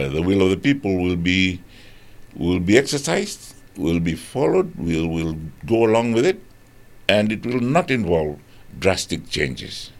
Prime Minister Sitiveni Rabuka has acknowledged the challenges faced by the coalition government during an interview on Radio Fiji One’s “Na Noda Paraiminista” program.